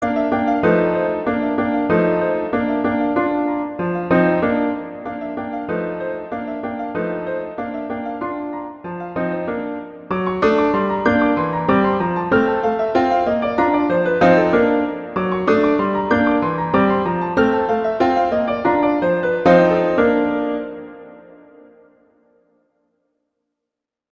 Instrumentation: piano solo
classical, children